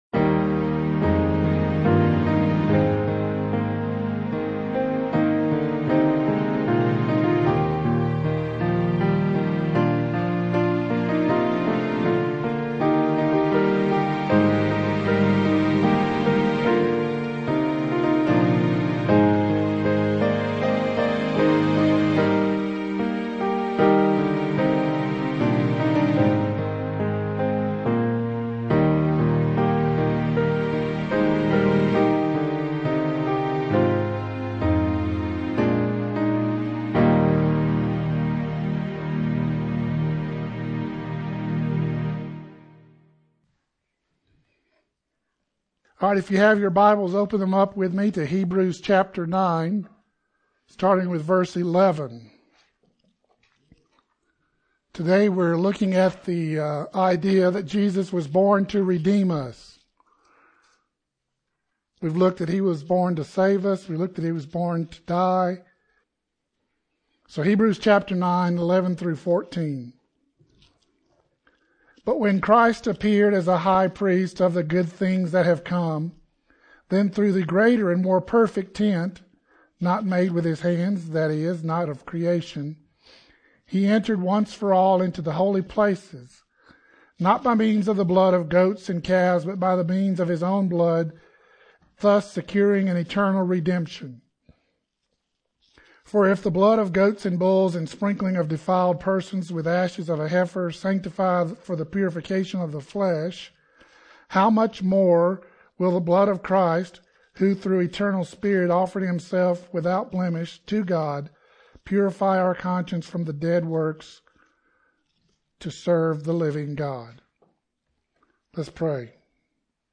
1 Sermon - Jesus Was Born To Redeem Us 37:58